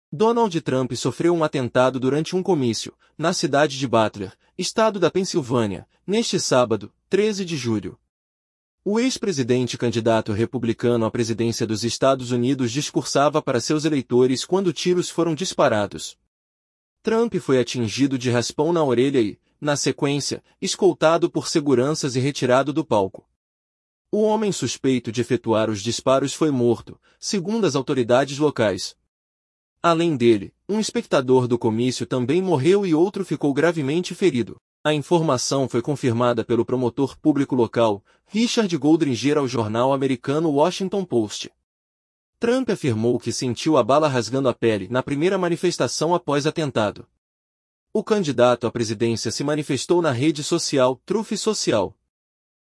VÍDEO: Donald Trump é atingido por tiros durante comício na Pensilvânia
O ex-presidente e candidato republicano à presidência dos Estados Unidos discursava para seus eleitores quando tiros foram disparados. Trump foi atingido de raspão na orelha e, na sequência, escoltado por seguranças e retirado do palco.